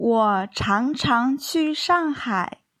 「去（qù　チュ）」という動詞の前に、頻度を表す「常常（chángcháng　チャンチャン）」という副詞を置いています。